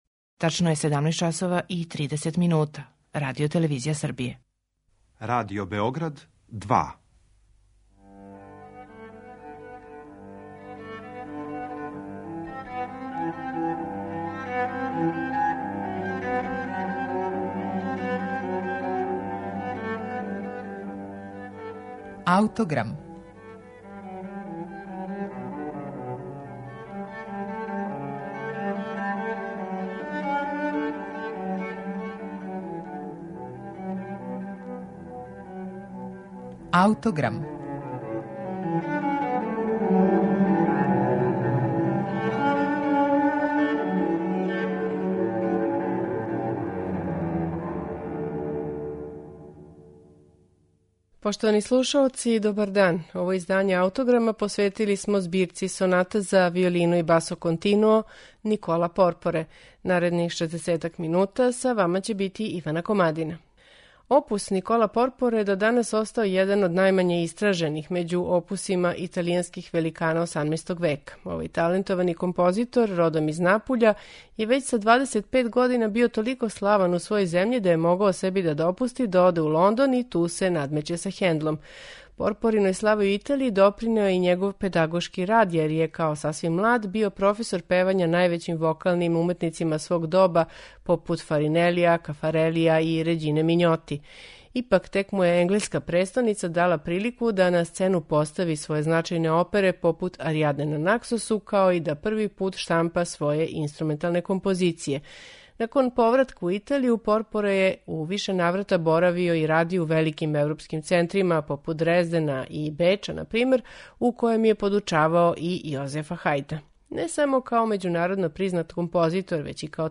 Чућете пет соната из збирке 12 соната за виолину и басо континуо, Никола Порпоре
на оригиналним инструментима 18. века
виолина
виолончело
чембало